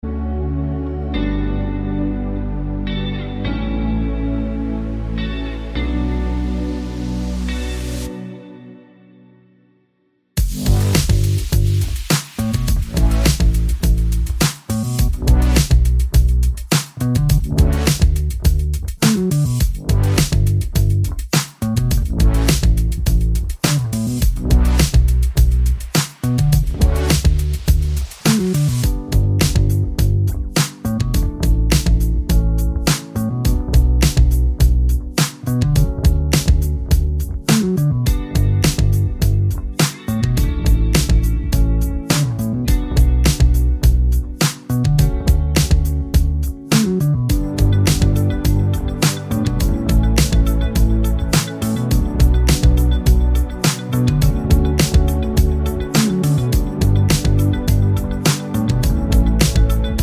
no Backing Vocals R'n'B / Hip Hop 3:34 Buy £1.50